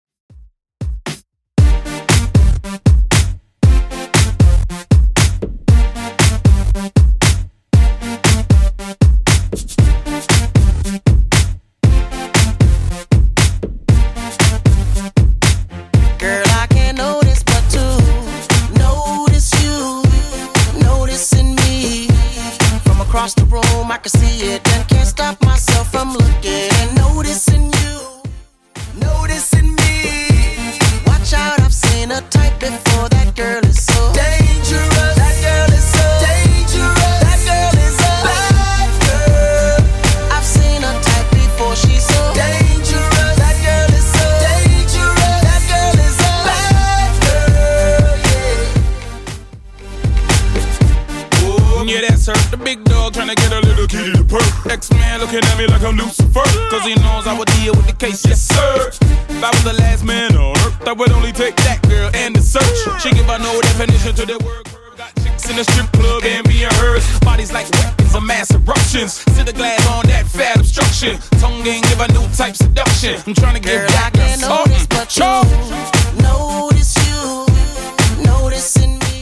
Genre: 80's Version: Clean BPM: 122